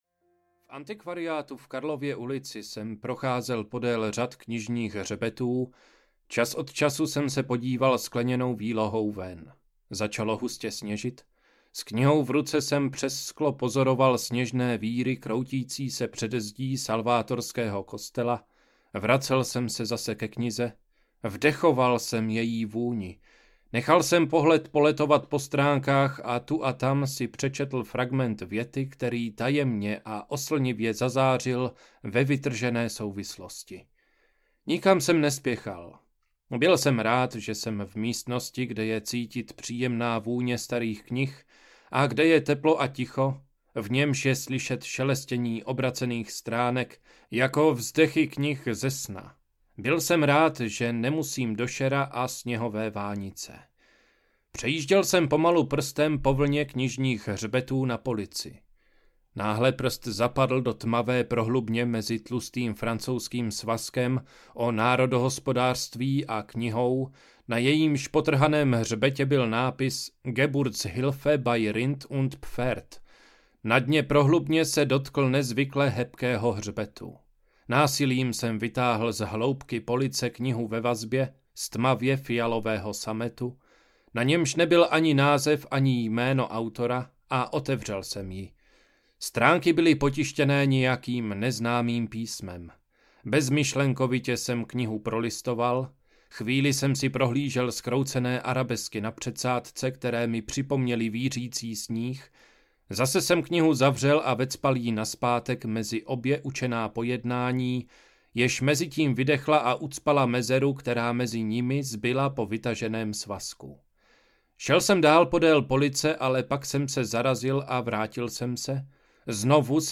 Druhé město audiokniha
Ukázka z knihy